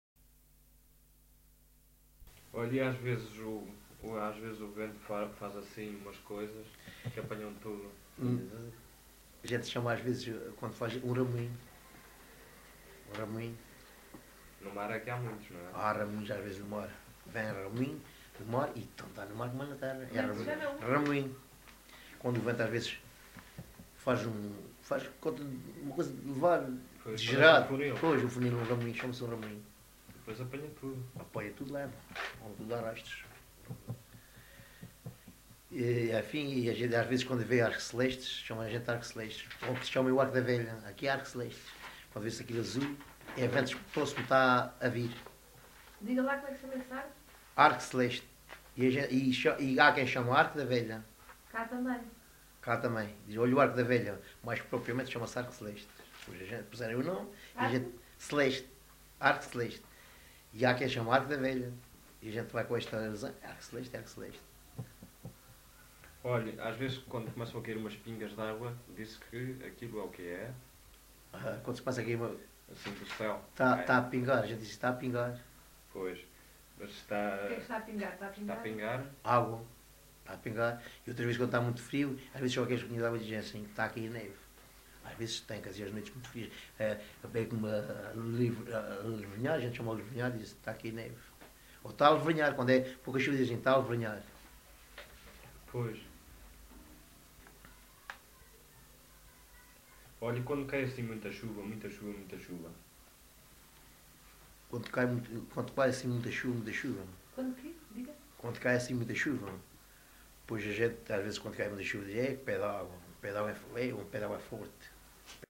LocalidadeAlvor (Portimão, Faro)